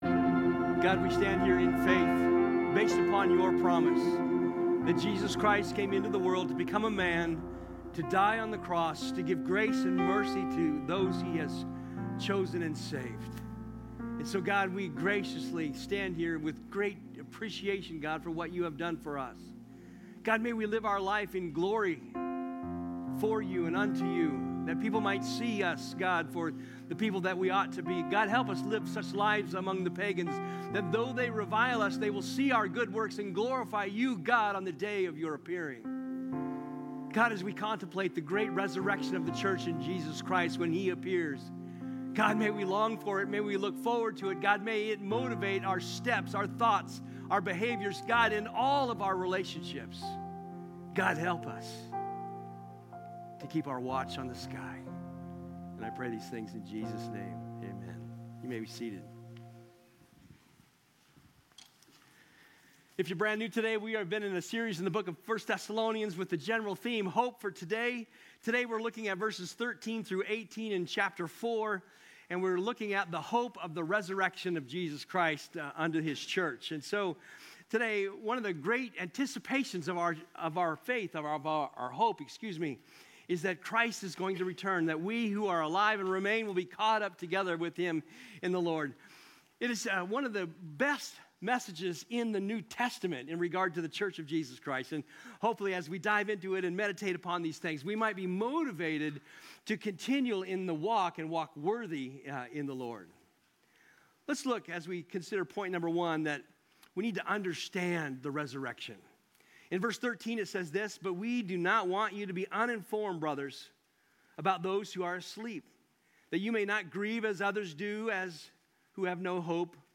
Sermon Archive | Avondale Bible Church